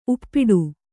♪ uppiṭṭu